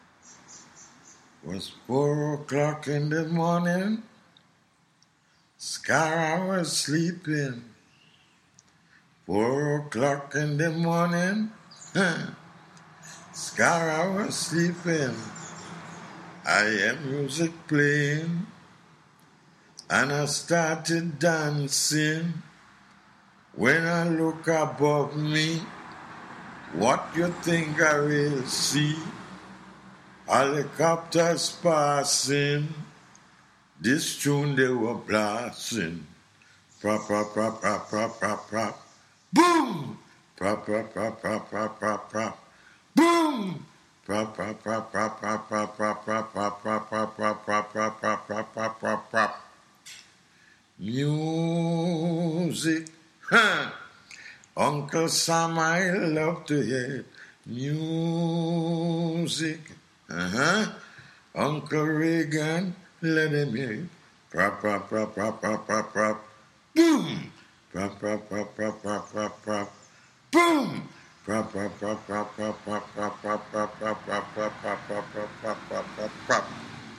sings (without accompaniment)
calypso